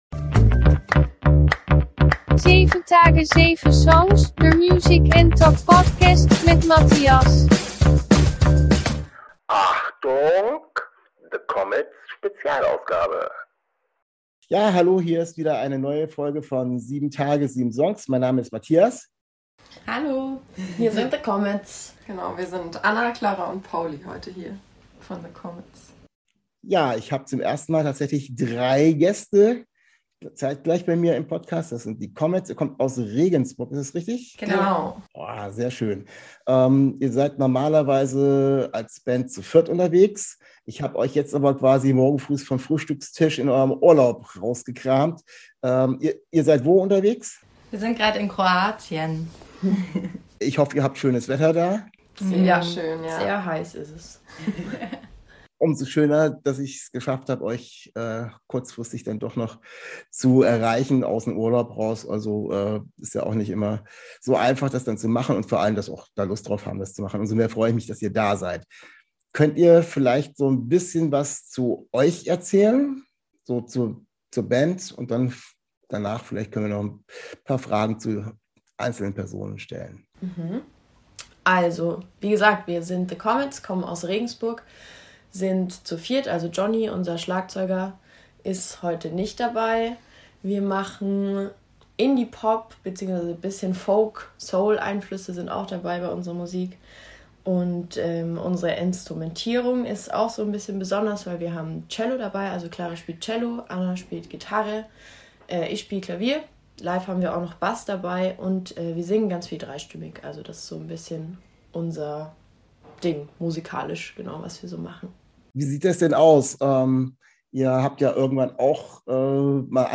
Letzte Episode #2.34 The Komets Special 13. August 2022 Nächste Episode download Beschreibung Kapitel Teilen Abonnieren In dieser Folge habe ich 3/4 der Band THE KOMETS zu Gast. Ich habe die drei Mädels direkt in ihrem Urlaub in Kroatien erwischt und wir haben in lockerer Atmosphäre über ihre Band, ihre aktuelle EP und ihre musikalischen Einflüsse gesprochen.